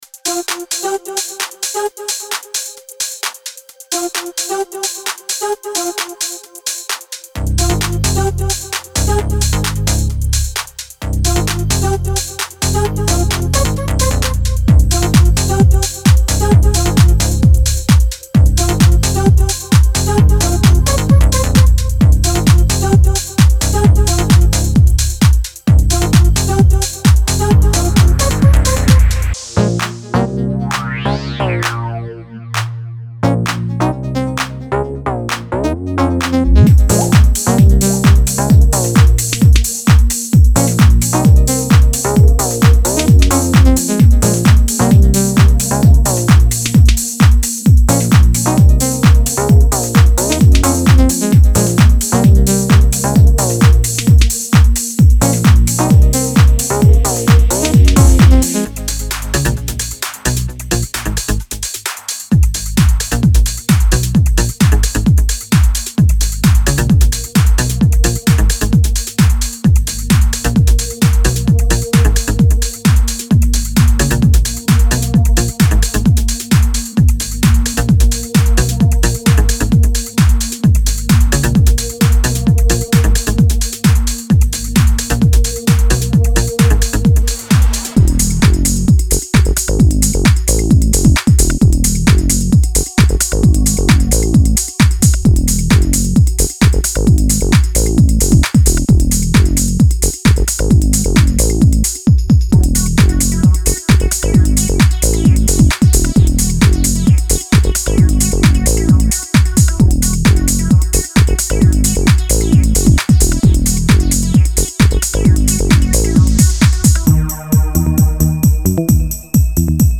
尽情沉浸在丰富的音色世界吧，这里有清脆的科技感鼓循环、暗黑前卫的合成器主音、厚重的低音贝斯以及饱满的氛围音垫。
循环乐段的速度范围为 130 至 133 BPM。